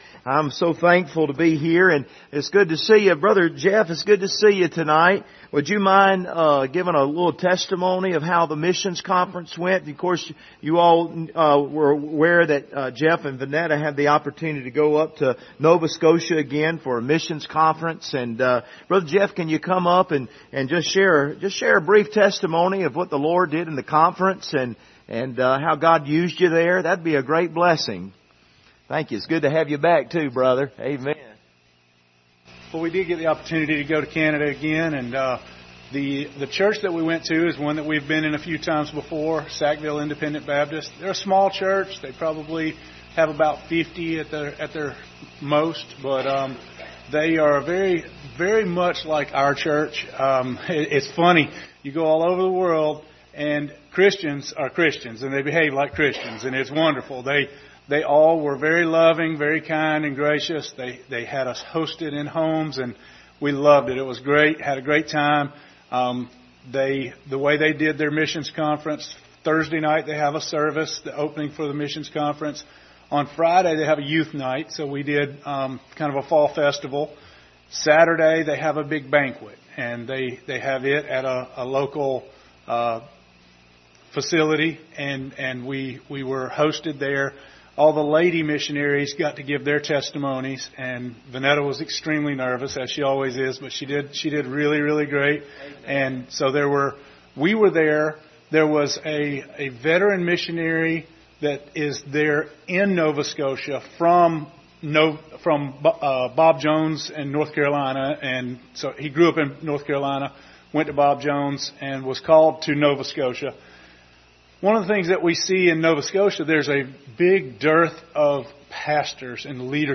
Ephesians 4:29-30 Service Type: Wednesday Evening Topics: friendship , testimony « What Will You Be Remembered For?